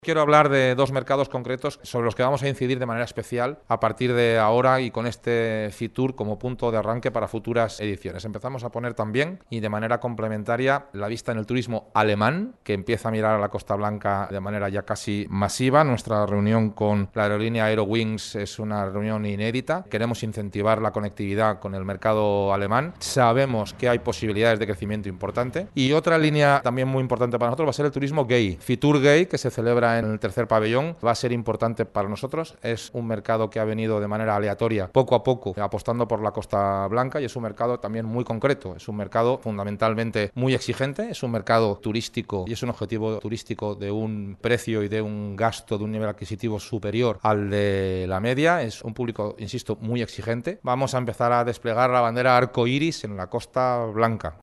El presidente de la Diputación de Alicante detalla el programa de actividades que desarrollará el Patronato Provincial en FITUR
CORTE-Presentación-FITUR-2022-Carlos-Mazón.mp3